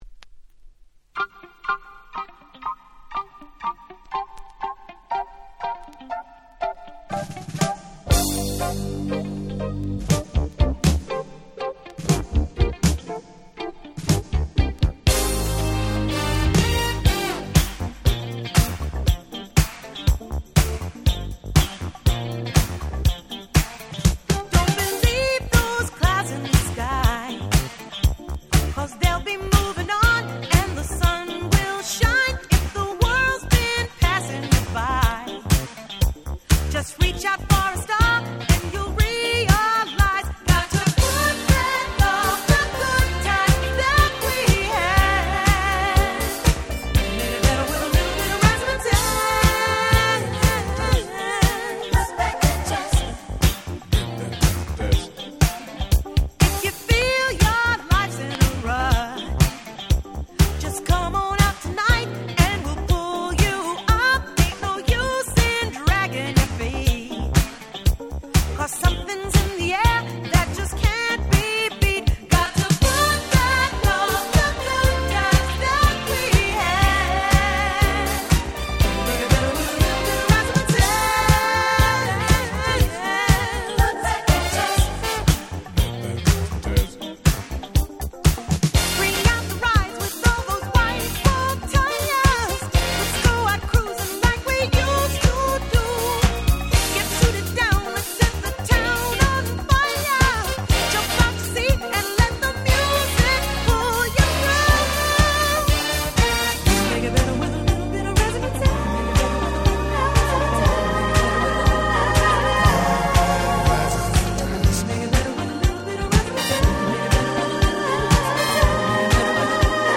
81' Smash Hit Disco / Boogie !!